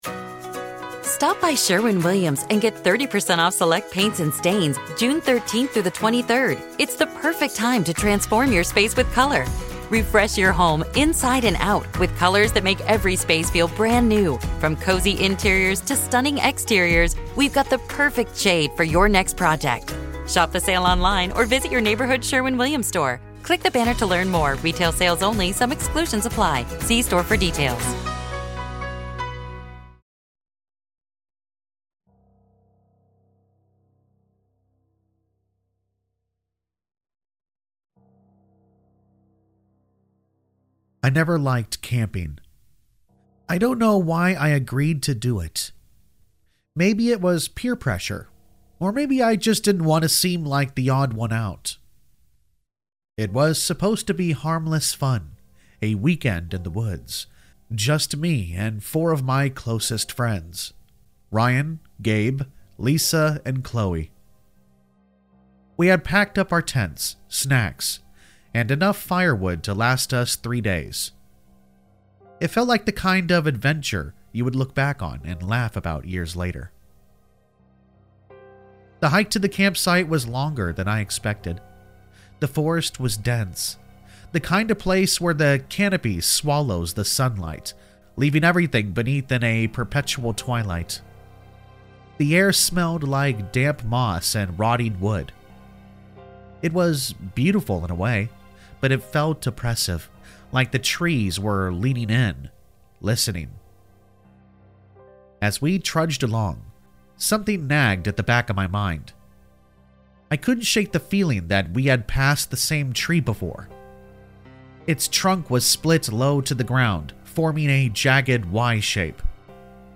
Get ready for a spine-chilling horror story that will leave you on the edge of your seat! Campers beware, as a terrifying tale unfolds in the dark forest.